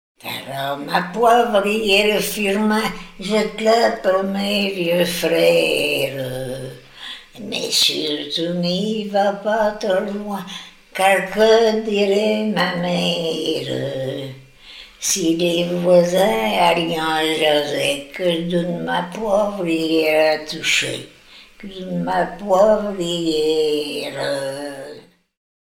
Chants brefs - A danser
danse : branle
Pièce musicale éditée